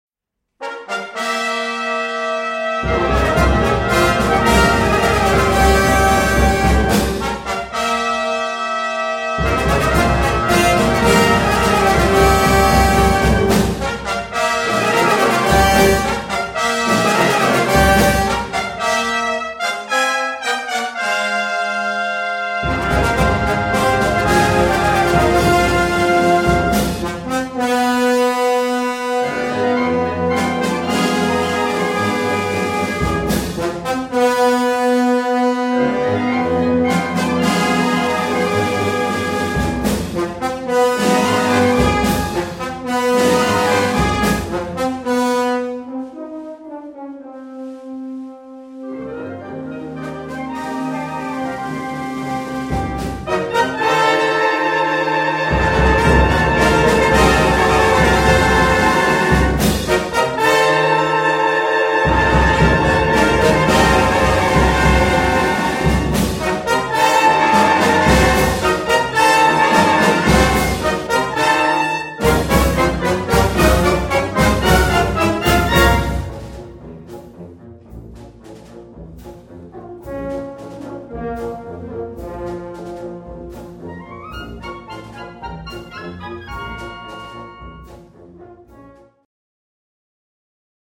symphonic and military marches and scherzos